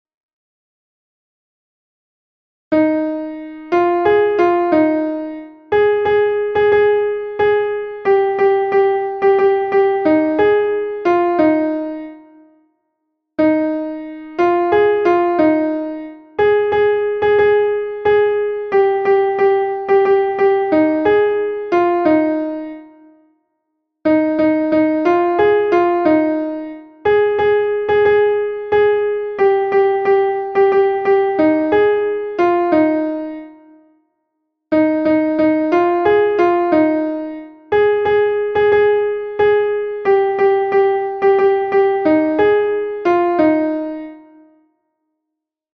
Chant Commun.
Alto
Tchotsholoza-Alto.mp3